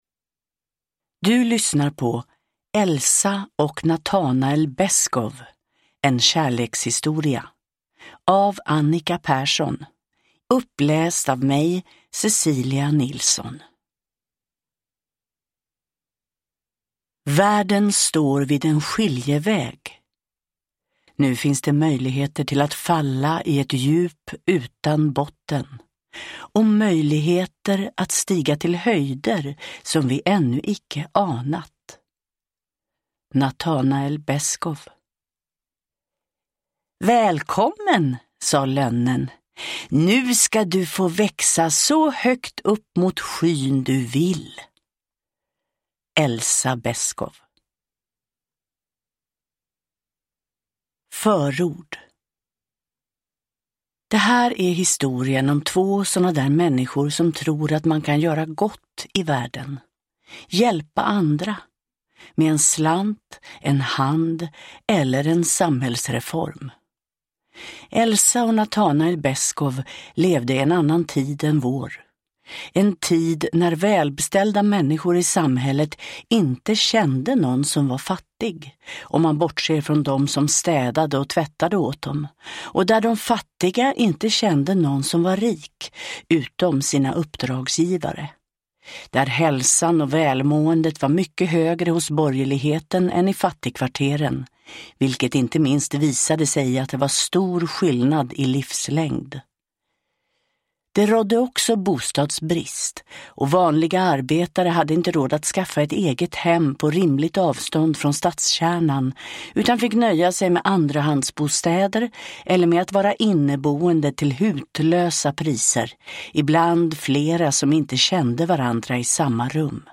Elsa och Natanael Beskow : en kärlekshistoria – Ljudbok